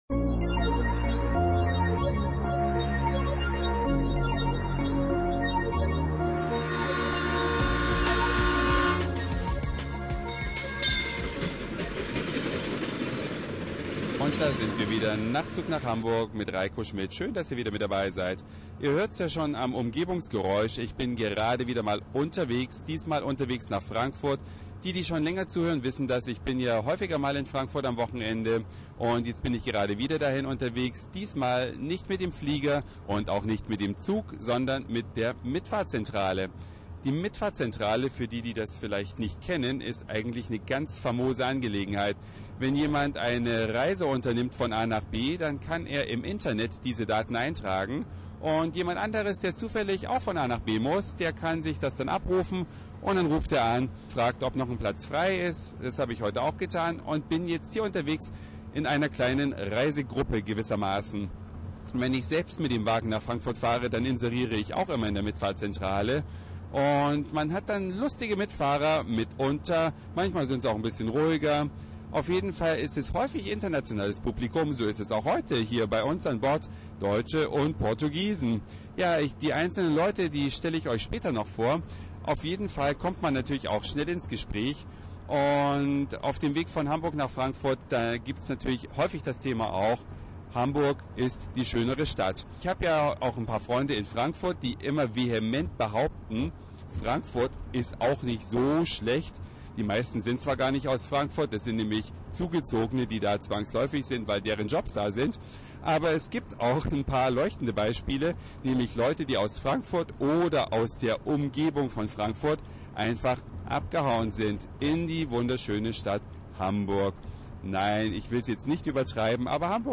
Eine Reise durch die Vielfalt aus Satire, Informationen, Soundseeing und Audioblog.
Unterwegs von Frankfurt nach Hamburg mit der Mitfahrzentrale